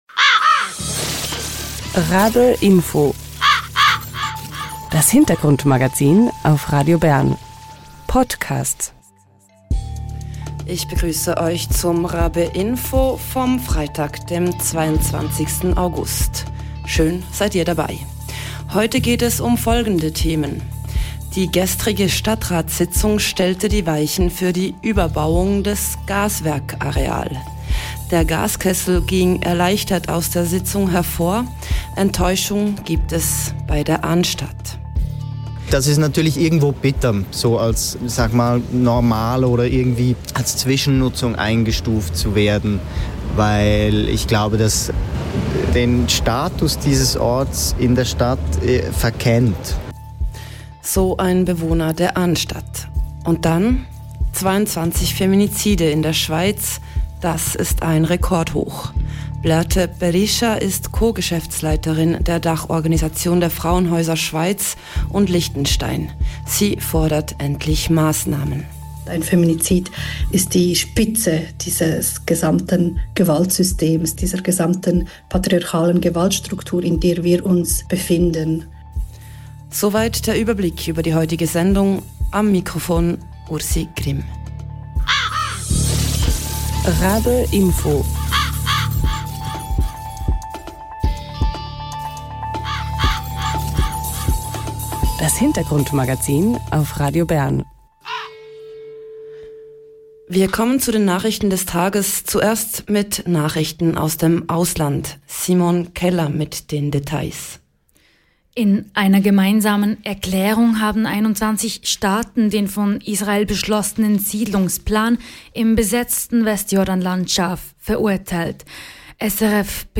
Im Interview spricht sie über die Ursachen dieses strukturellen Problems.